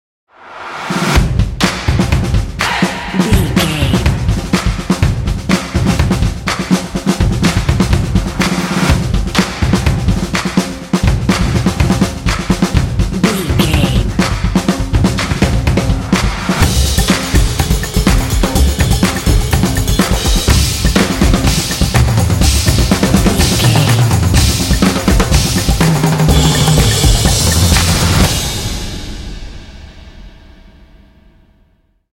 This epic drumline will pump you up for some intense action.
Epic / Action
Atonal
driving
motivational
drums
percussion
drumline